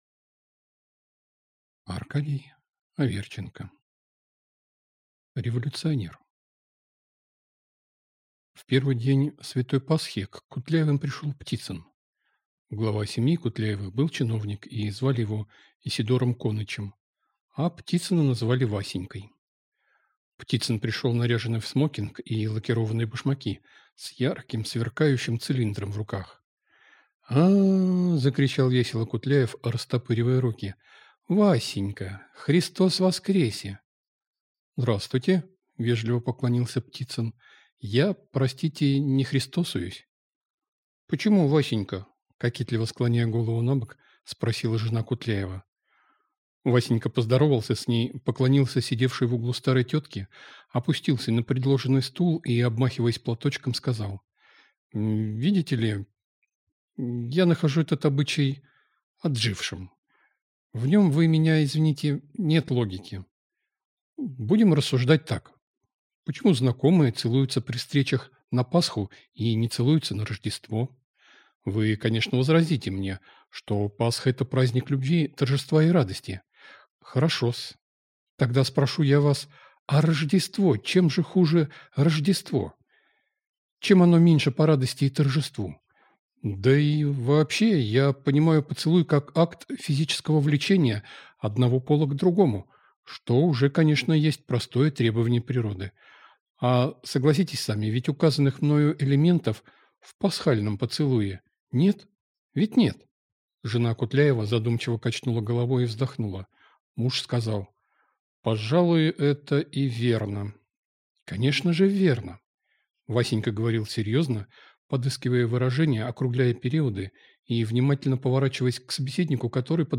Аудиокнига Революционер | Библиотека аудиокниг
Прослушать и бесплатно скачать фрагмент аудиокниги